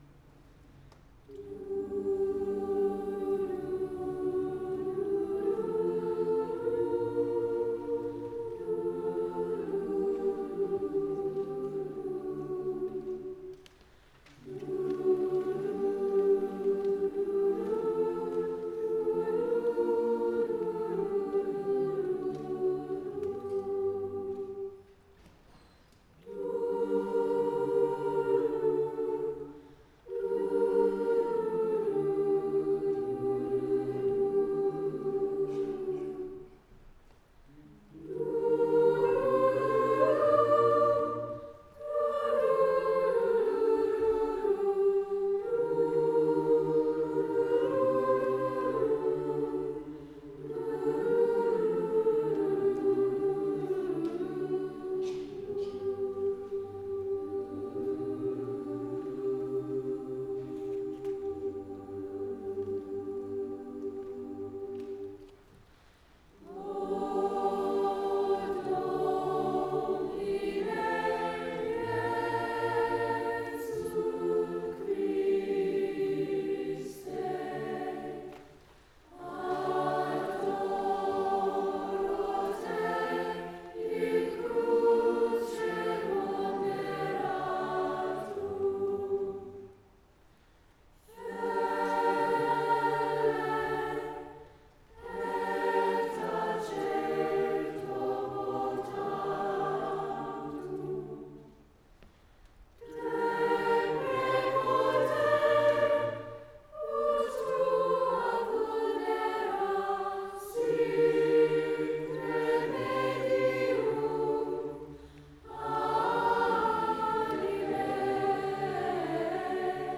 the students
Chorus